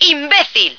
flak_m/sounds/female2/est/F2sucker.ogg at trunk